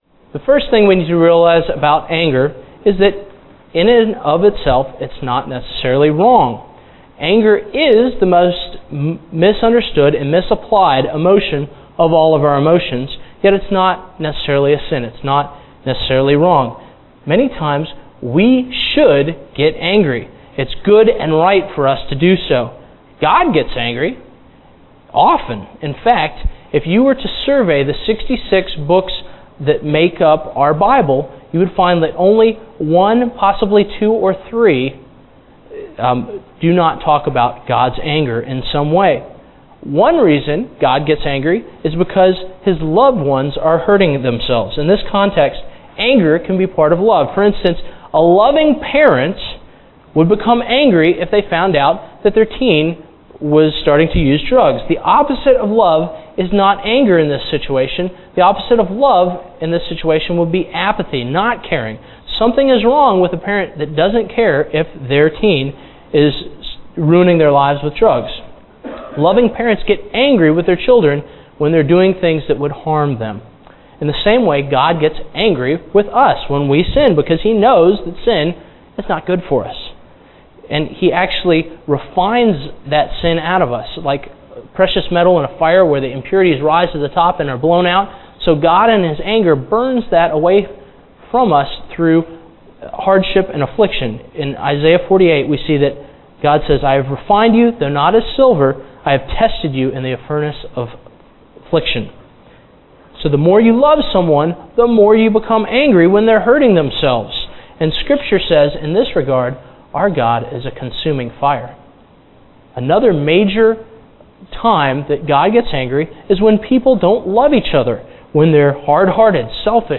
A message from the series "40 Days of Love."